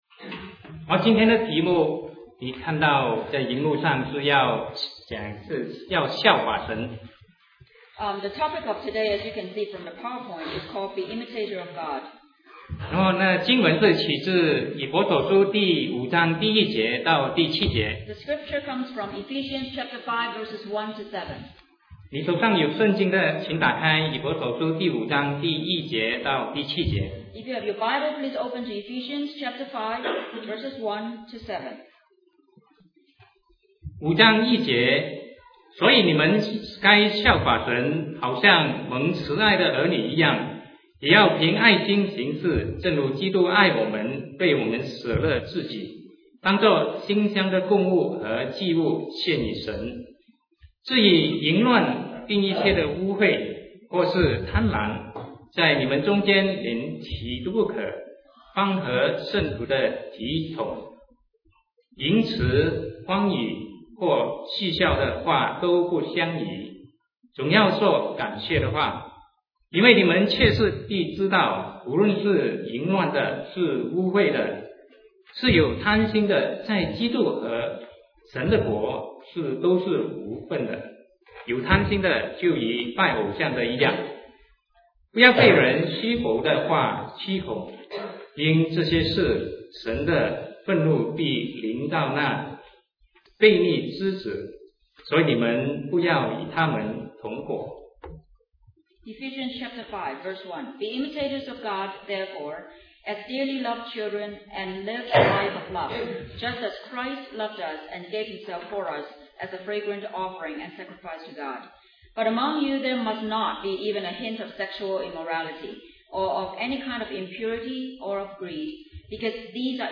Sermon 2008-09-21 Be Imitator of God